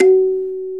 SANZA 1 F#3.WAV